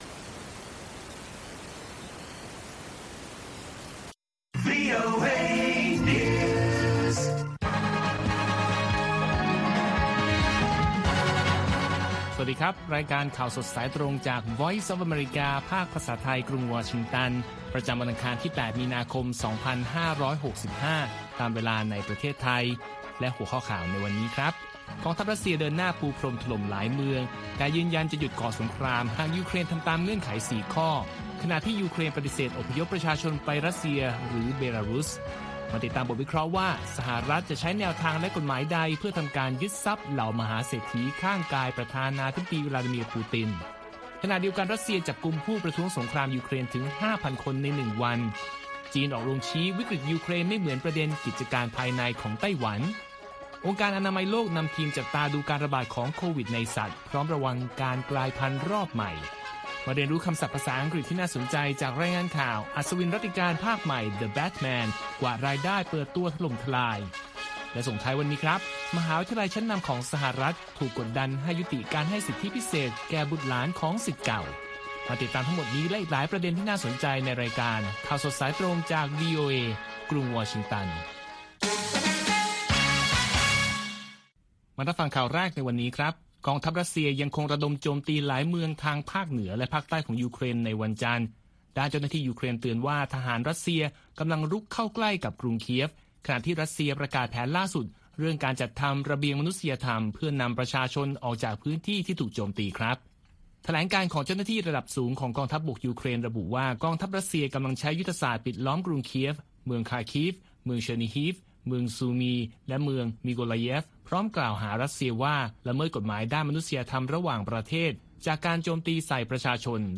ข่าวสดสายตรงจากวีโอเอ ภาคภาษาไทย ประจำวันอังคารที่ 8 มีนาคม 2565 ตามเวลาประเทศไทย